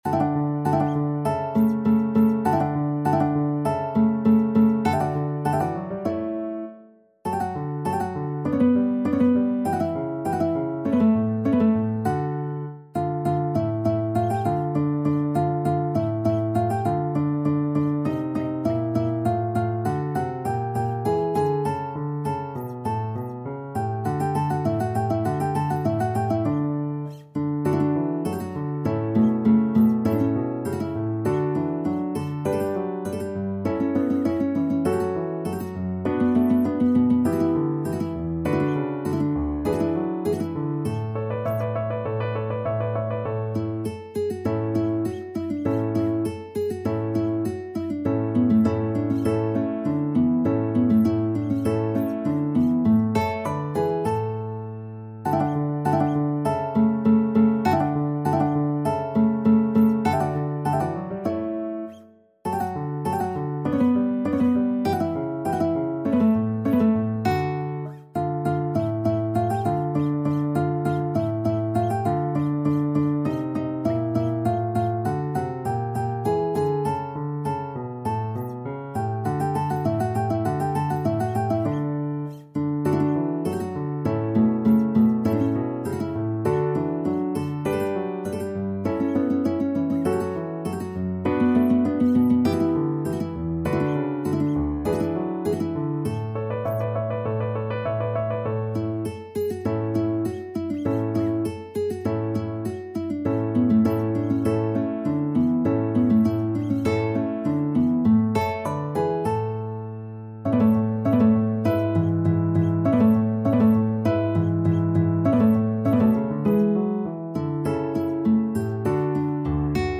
Classical Vivaldi, Antonio CONCERTO in D major 1st movement (complete) Guitar version
Guitar
D major (Sounding Pitch) (View more D major Music for Guitar )
4/4 (View more 4/4 Music)
D4-Db6
Classical (View more Classical Guitar Music)